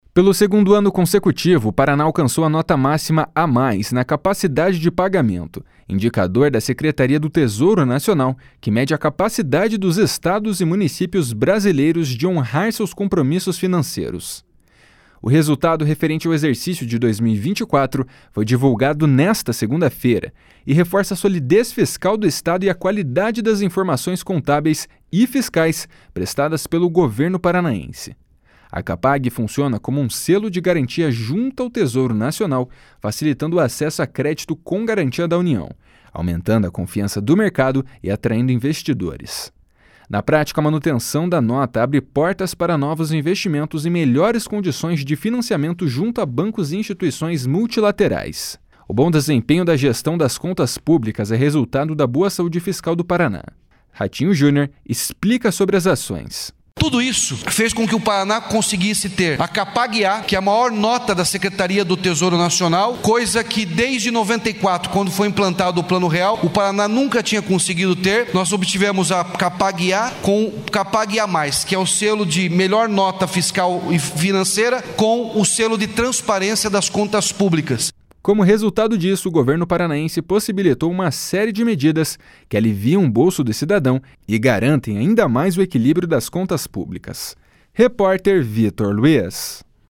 Ratinho Junior explica sobre as ações. // SONORA RATINHO JUNIOR //
Repórter